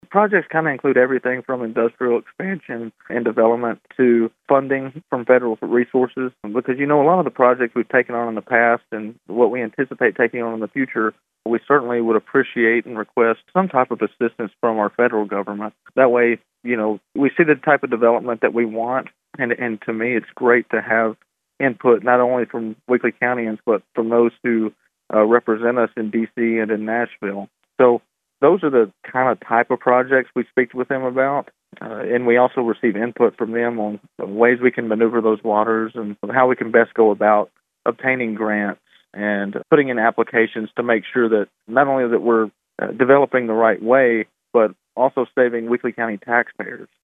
Mayor Hutcherson told Thunderbolt News about the types of projects they will be discussing with Senator Hagerty and Congressman Kustoff.